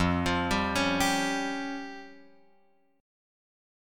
Fsus2#5 chord {1 x 3 0 2 3} chord